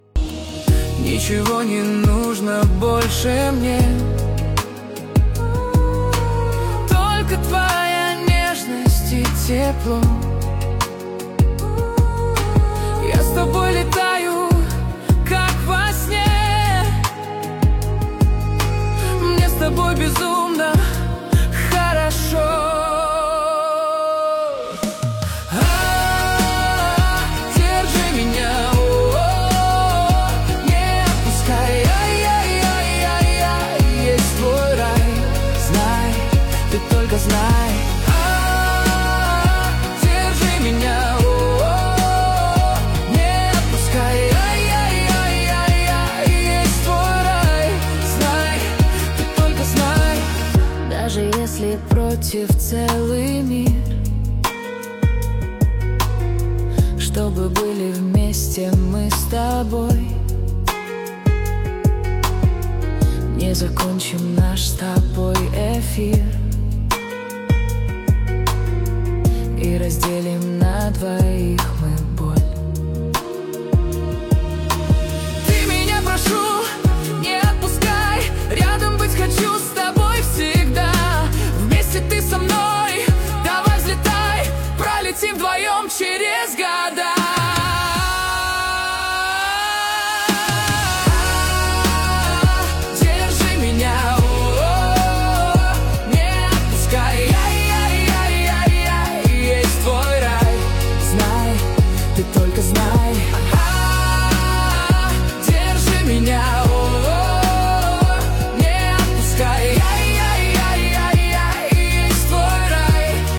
13 декабрь 2025 Русская AI музыка 90 прослушиваний